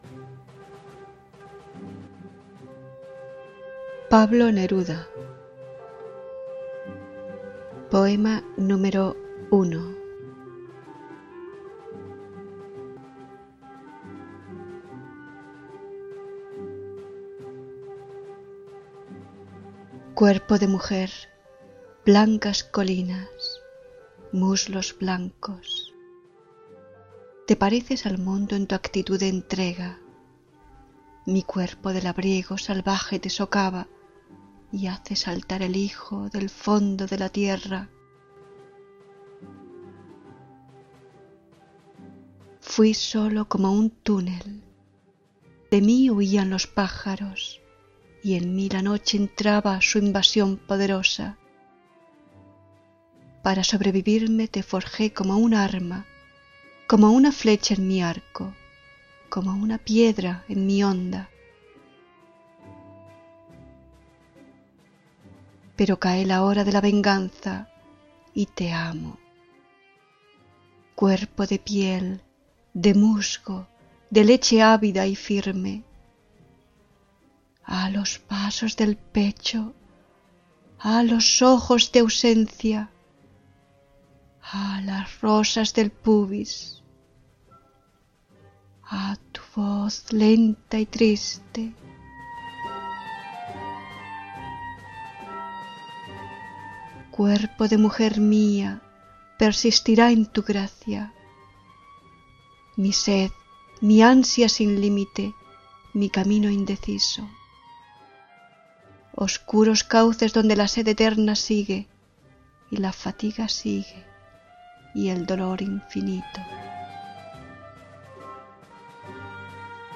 Аудио-файл (оригинал на испанском языке)